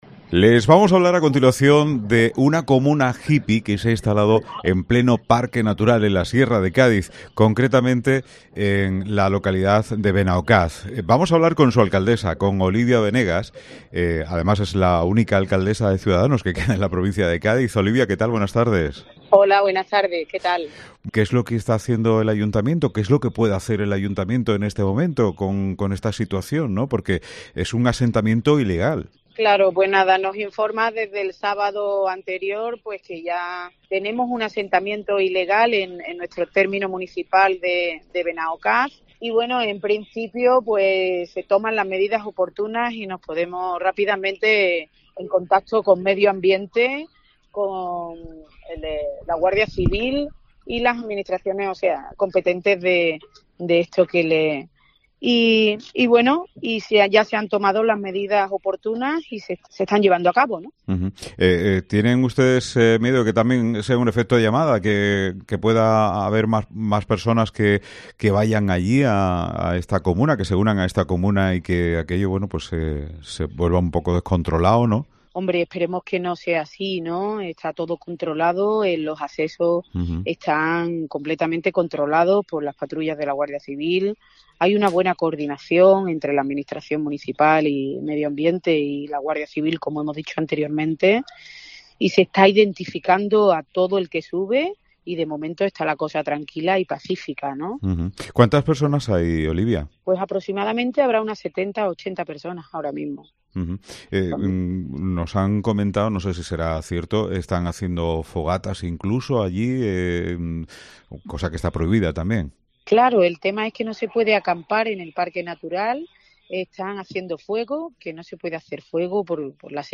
Entrevistamos a la alcaldesa de Benaocaz, Olivia Venegas, sobre el asentamiento ilegal hippie